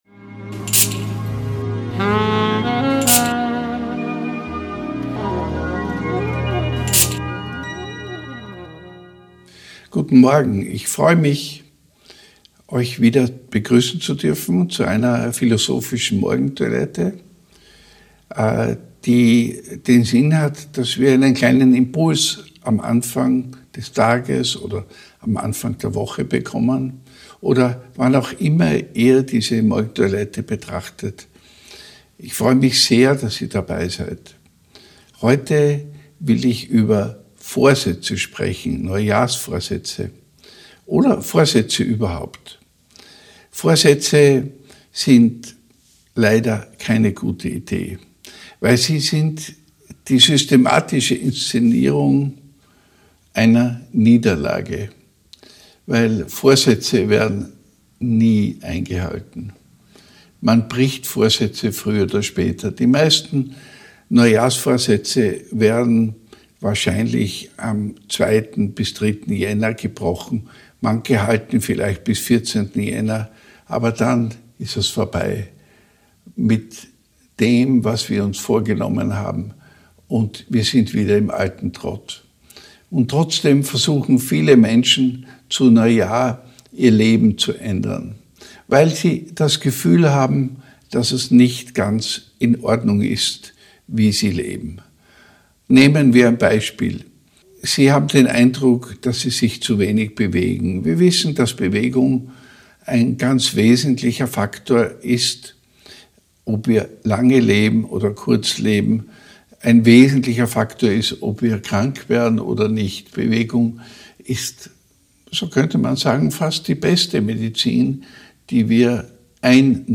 Ein ruhiger Impuls für den Tages- oder Jahresbeginn.